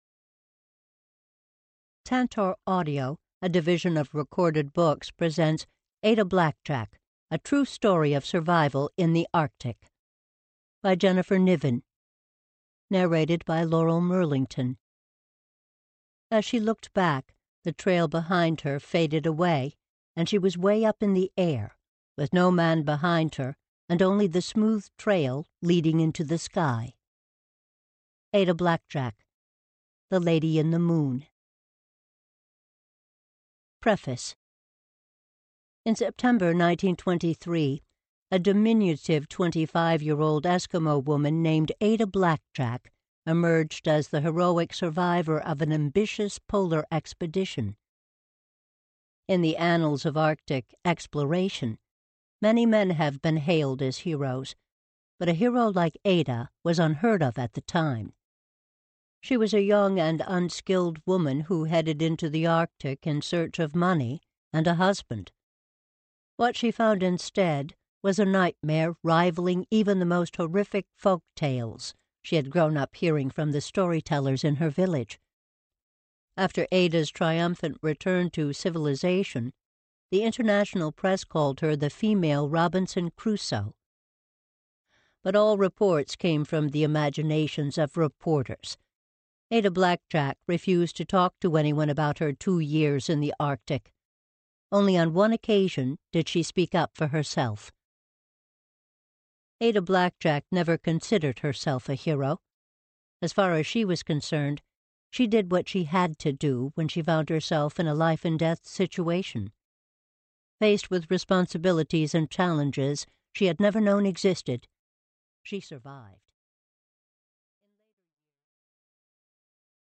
digital digital digital stereo audio file Notes